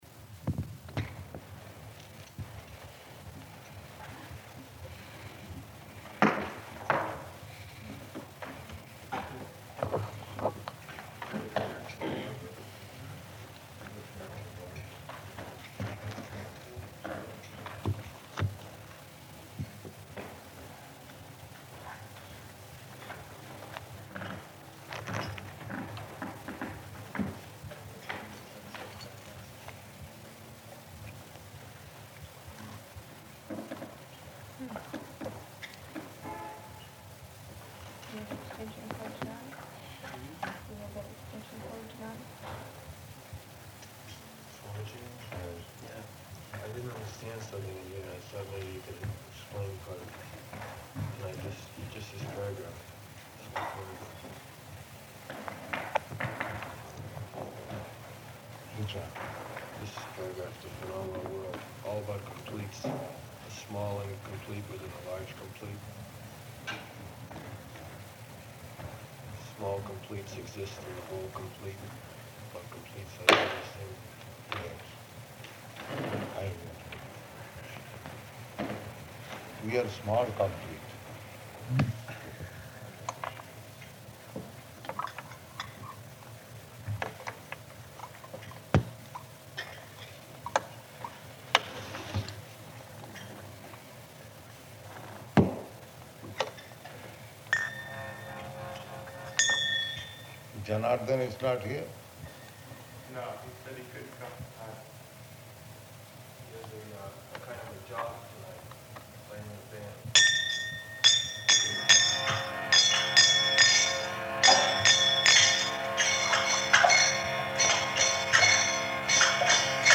Bhagavad-gītā 4.8 --:-- --:-- Type: Bhagavad-gita Dated: June 14th 1968 Location: Montreal Audio file: 680614BG-MONTREAL.mp3 [indistinct background talk amongst devotees] Lady devotee: [aside to other devotee] You have that extension cord?